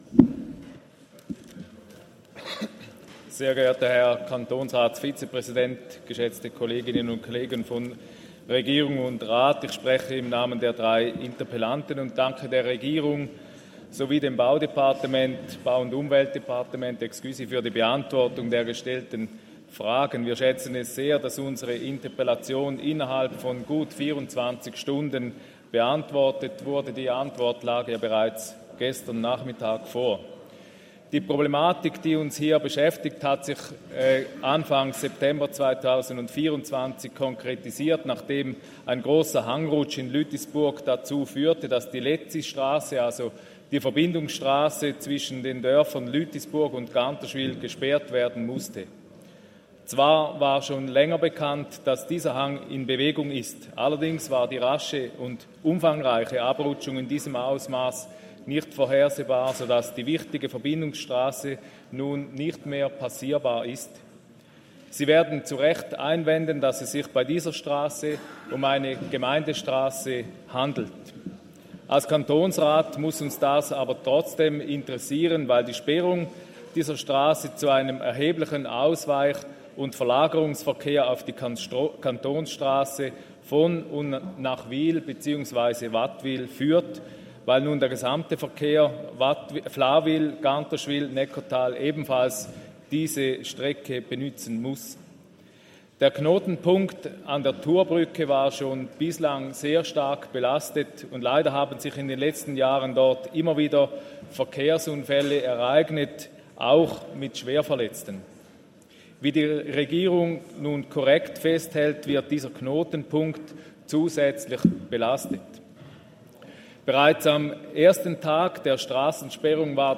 18.9.2024Wortmeldung
Session des Kantonsrates vom 16. bis 18. September 2024, Herbstsession